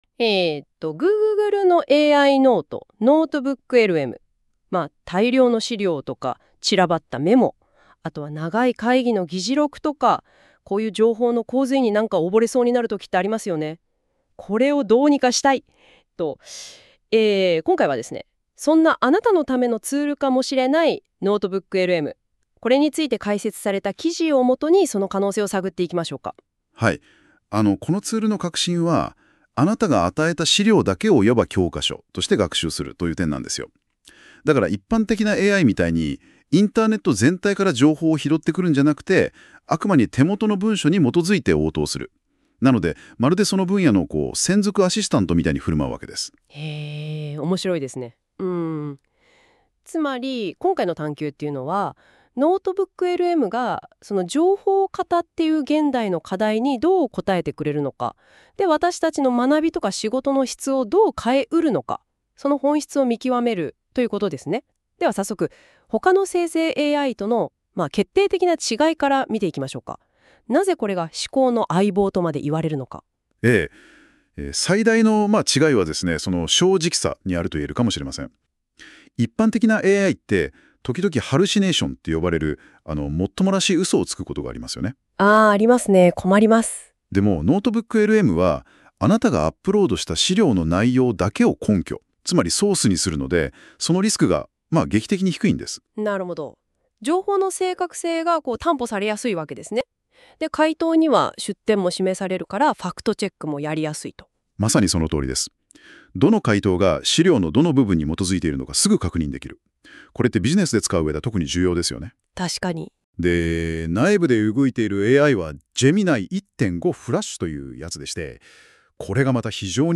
※Google の AI「NotebookLM」で生成した対話形式の音声解説です。
単なるテキストの読み上げとは異なり、AIが複数のペルソナ（人格）を使い分け、対話形式のポッドキャストのように内容を解説。
2人のホストがトピックを掘り下げ、関連付ける活発な会話形式。